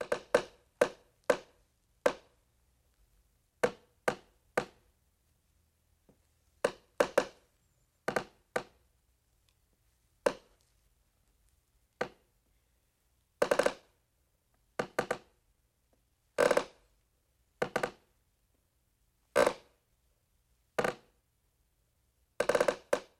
古老的吱吱声 " G2707往返曲柄
描述：吱吱作响的中等重量曲柄。一些来回拉动作。
我已将它们数字化以便保存，但它们尚未恢复并且有一些噪音。